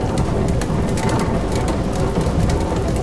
Add TA1 watermill
techage_mill.ogg